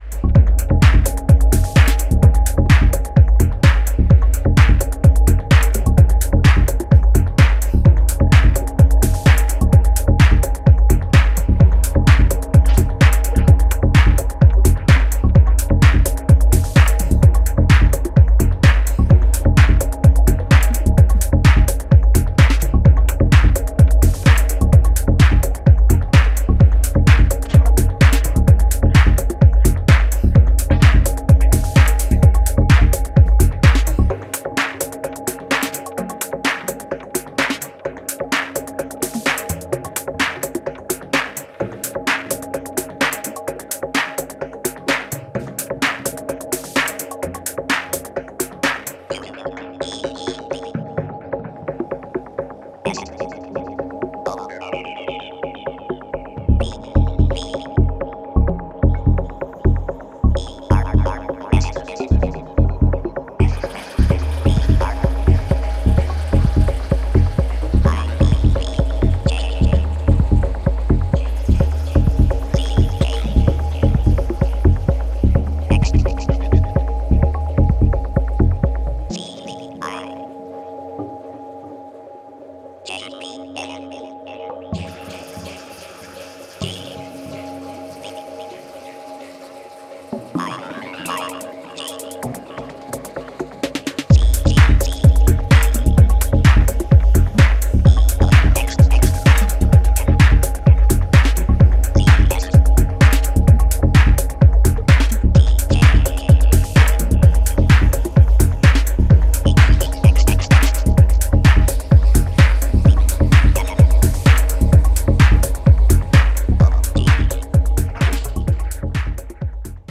朧げにリフレインするパッドと加工されたヴォイス・サンプルが脳内に直接響いてるいかのうよな